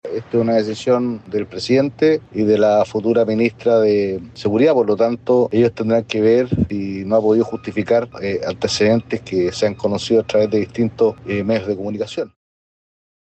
Asimismo, el diputado Juan Manuel Fuenzalida (UDI) sostuvo que se trata de una decisión que compete al presidente electo y a la futura ministra de Seguridad, Trinidad Steinert.